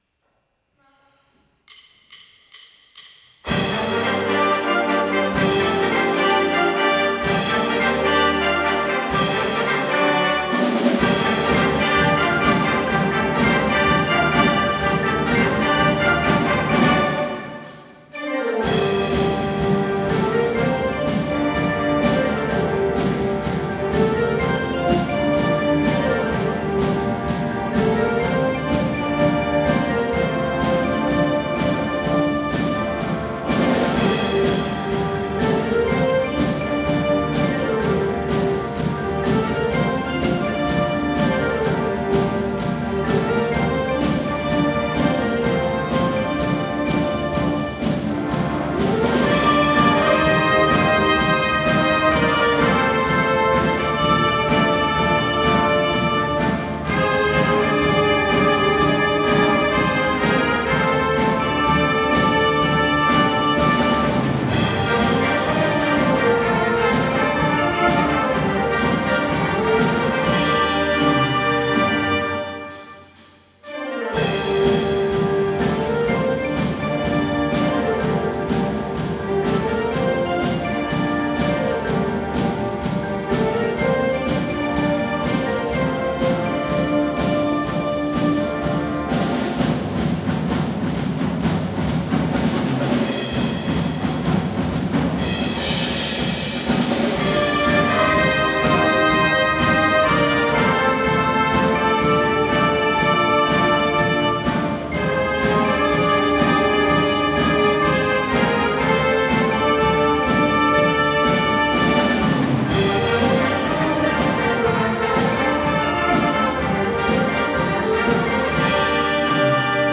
ホールで楽しむ日 12月　三重県文化会館大ホール
とある理由から、なんと三重県文化会館の大ホールで練習できることになりました。
そして、今日の目的といえば、 大好きな曲を録音することです。